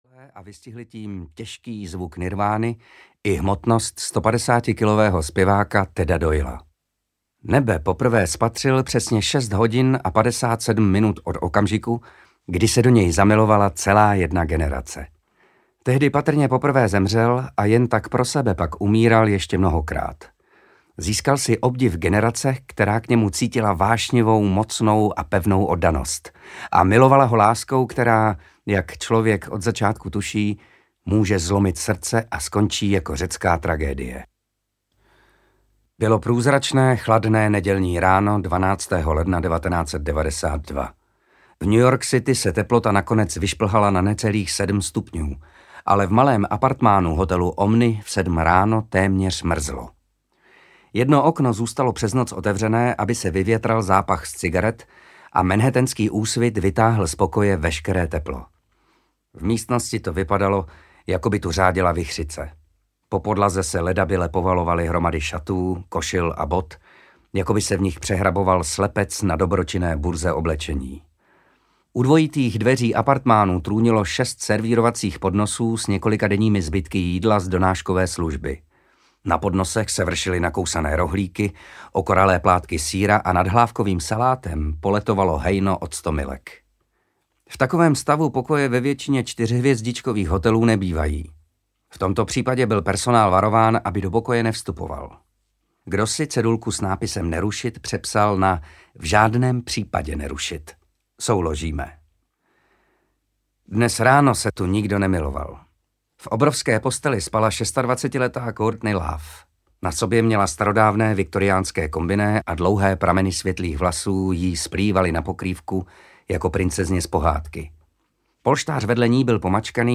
Ukázka z knihy
• InterpretMatěj Hádek